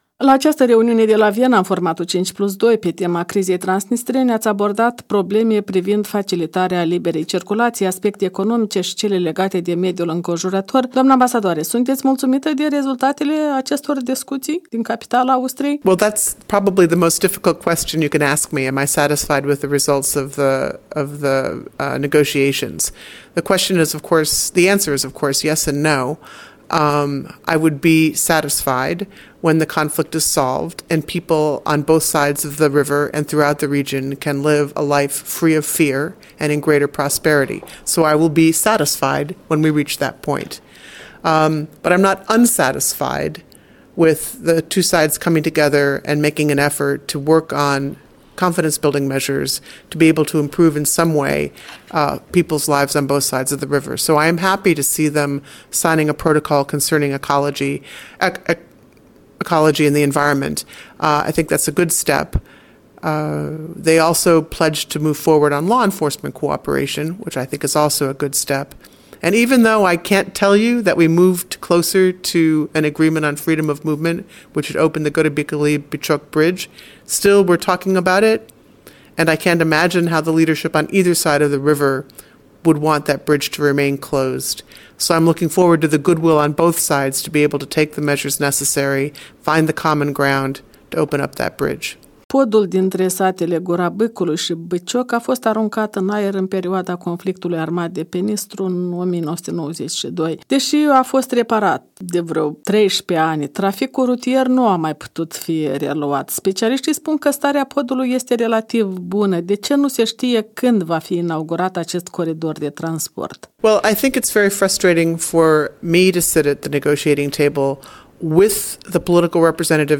În dialog cu Jennifer Brush, șefa misiunii OSCE de la Chișinău despre ultima rundă de negocieri 5+2